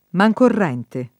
vai all'elenco alfabetico delle voci ingrandisci il carattere 100% rimpicciolisci il carattere stampa invia tramite posta elettronica codividi su Facebook mancorrente [ ma j korr $ nte ] (meno com. manicorrente ) s. m.